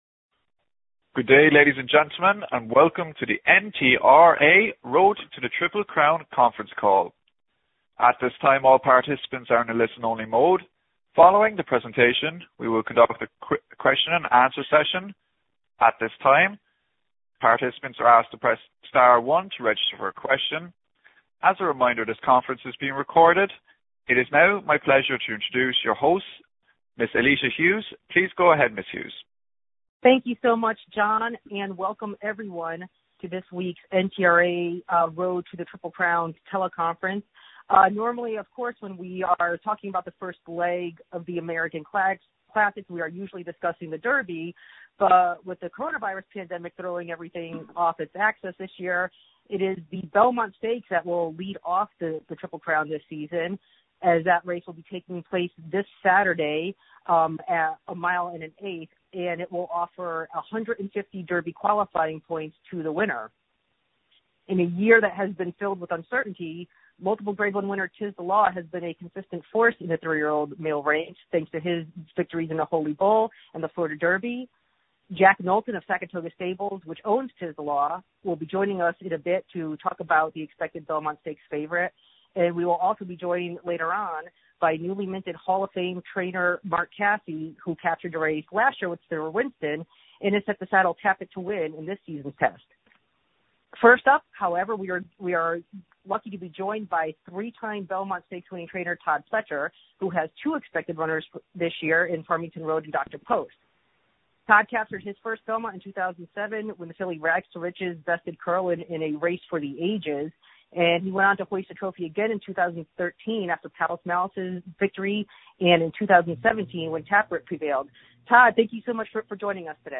National Media Teleconference